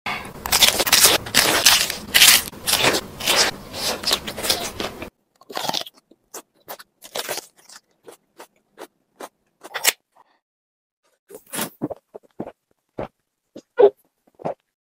Random Color food mukbang Korean sound effects free download
Random Color food mukbang Korean ASMR Testing mukbang Eating Sounds Shorts